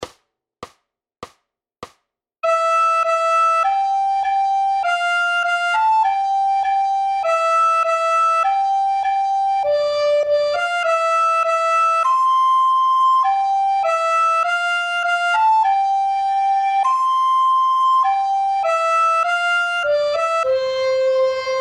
Hudební žánr Vánoční písně, koledy